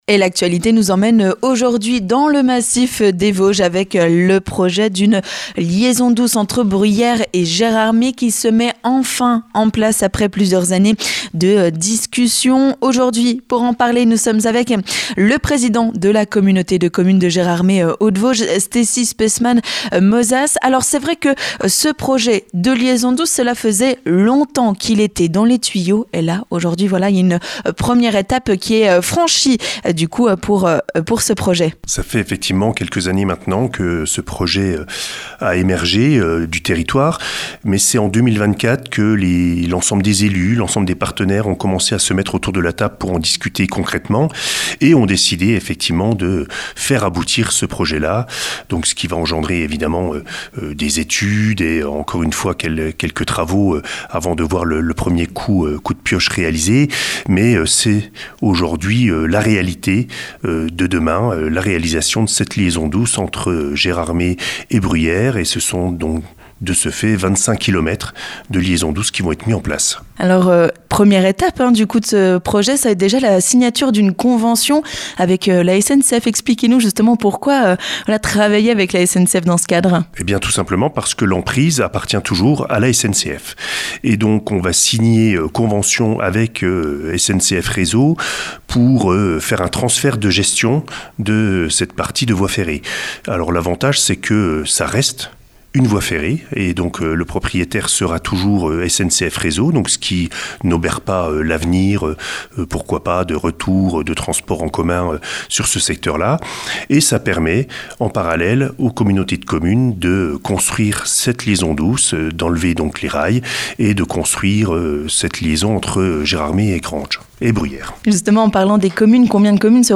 On en parle avec le président de la Communauté de communes de Gérardmer Hautes-Vosges, Stessy Speissmann-Mozas.